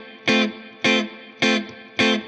DD_StratChop_105-Fmaj.wav